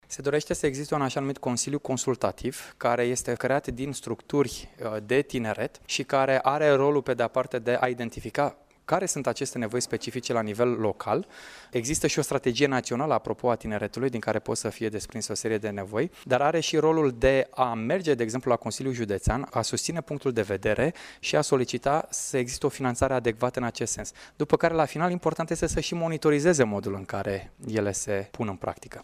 Andrei Popescu a mai afirmat că finanţările nu vor veni doar de la centru ci şi de la consiliile judeţene şi vor trebui introduse anumite mecanisme de monitorizare: